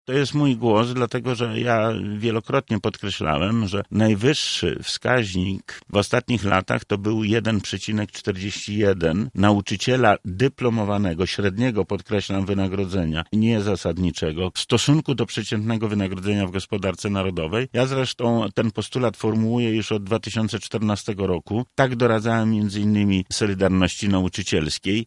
W Porannej Rozmowie Radia Centrum Lech Sprawka, poseł na Sejm VIII kadencji Prawa i Sprawiedliwości, były kurator oświaty i wiceminister edukacji, podkreślił, że wszystkie postulaty pedagogów ze strajku generalnego zostały spełnione.